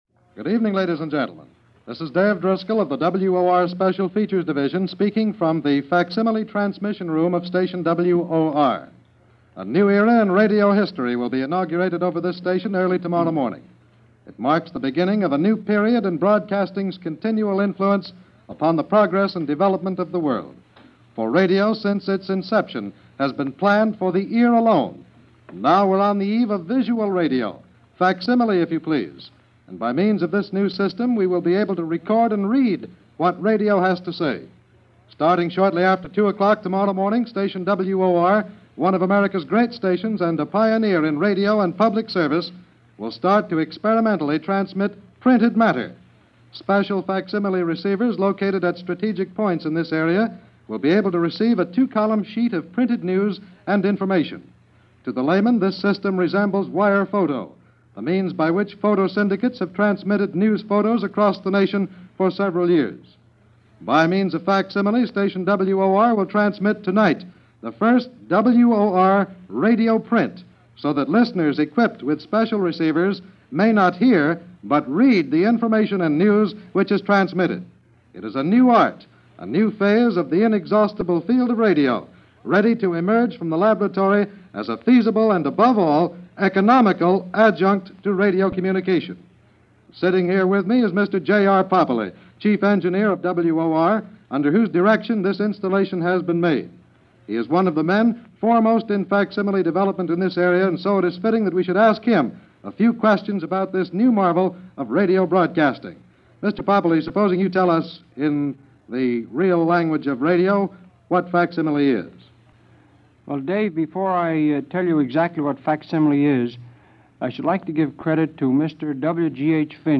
Here is that broadcast, and a discussion with the engineers of the Facsimile Machine as it was first heard on February 9, 1938.